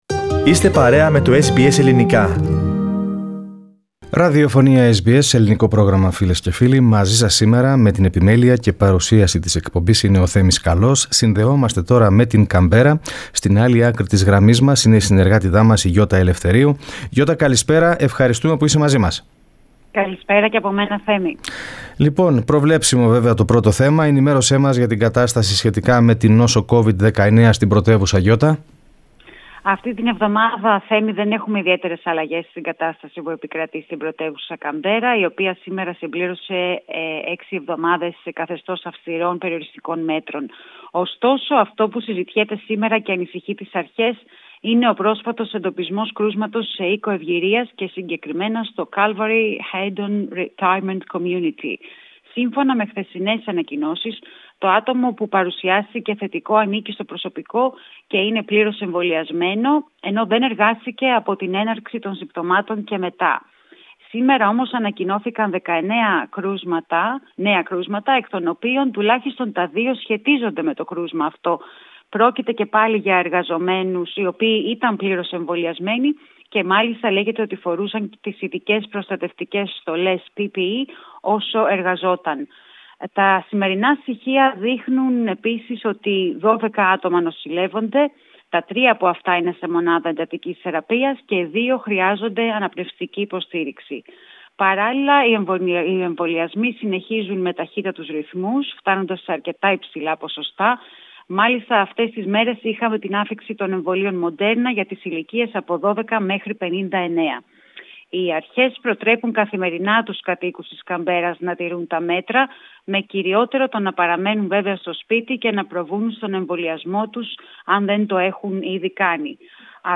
Ακούστε την είδηση στην ανταπόκριση από την Καμπέρα